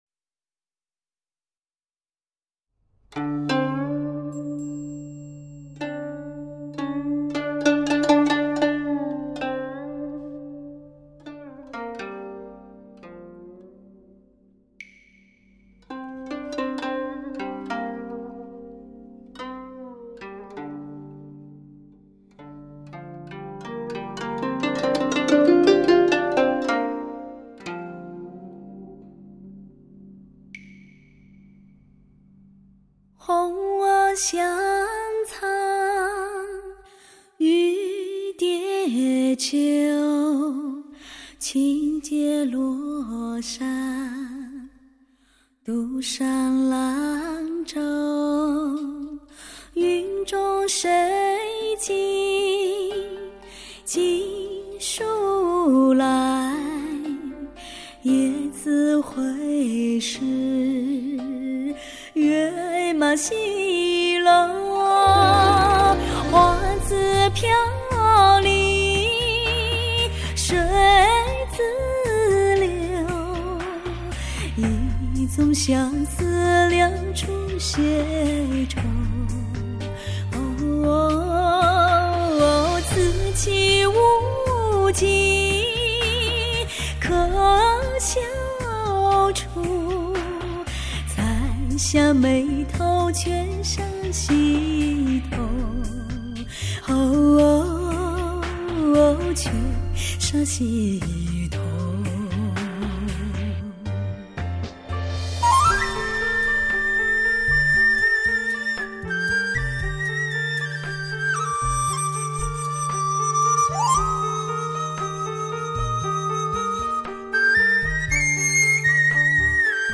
是有点傷感........